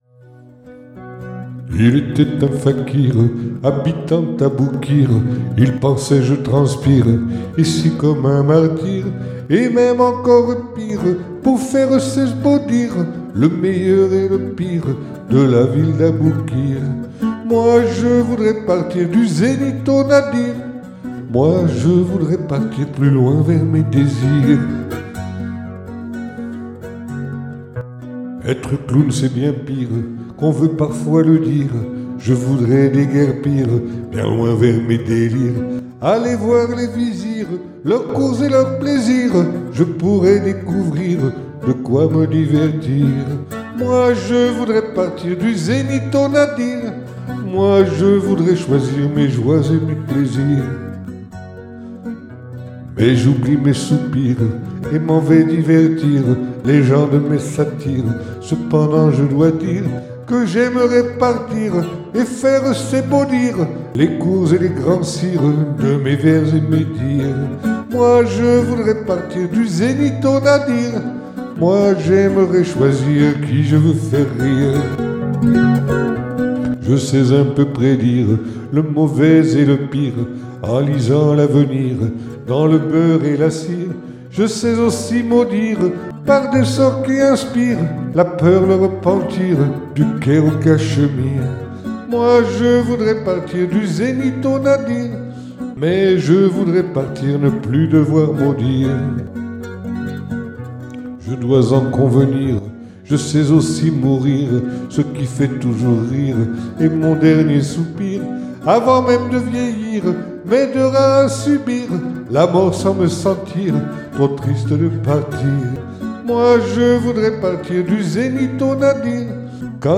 [Capo 2°]
le propos est plutôt guilleret